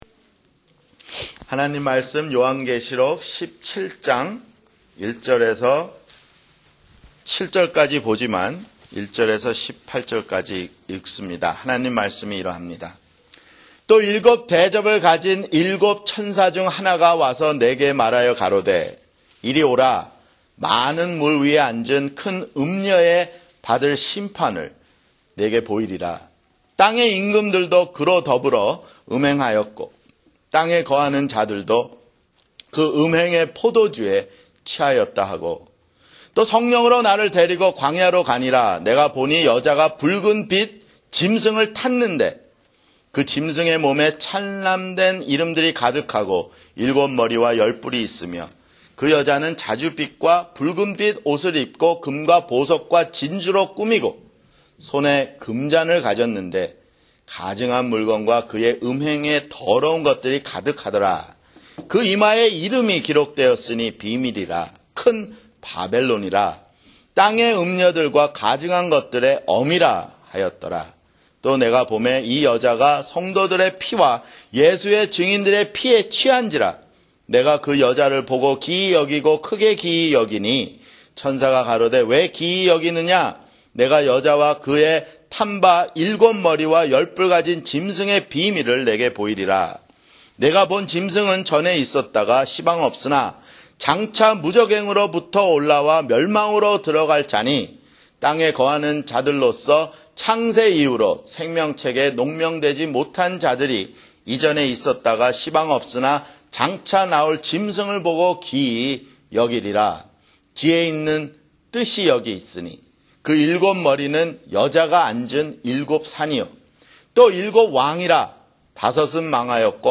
[주일설교] 요한계시록 (66)